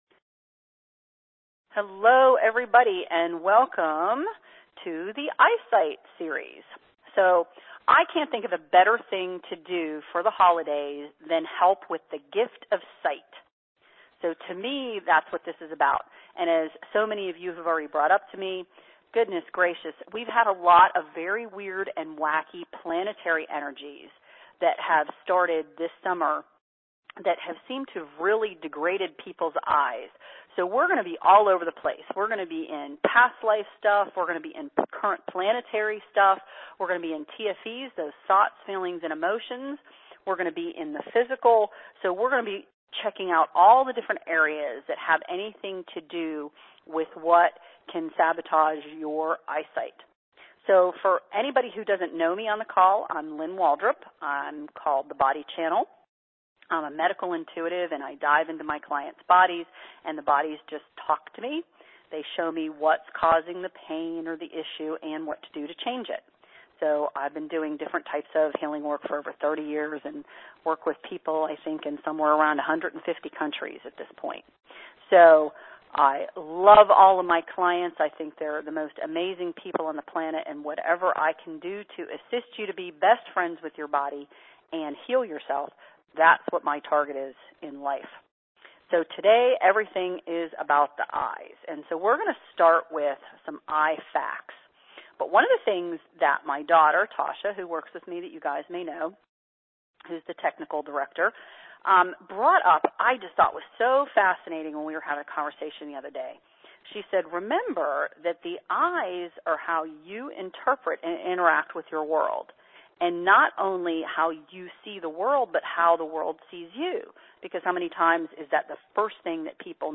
Eyesight+RR+Series+Interview.mp3